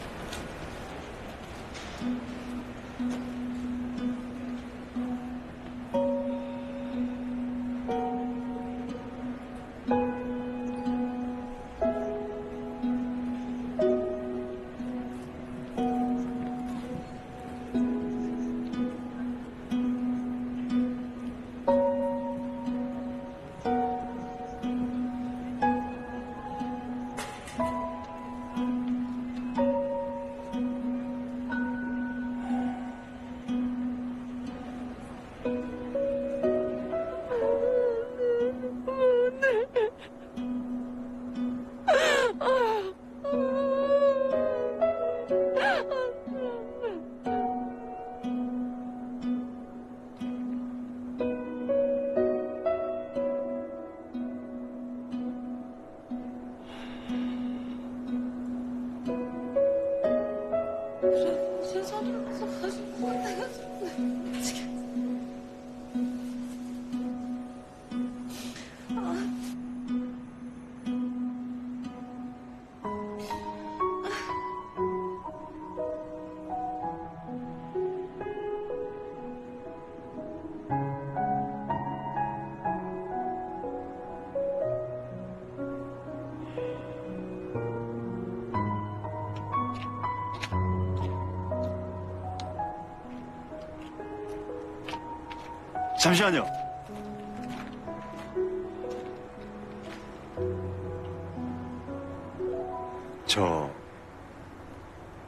一段韓劇的背景音樂, 簡單的音符, 波動出的頻率, 領著觀劇者, 不經意地, 心, 隨之沉靜…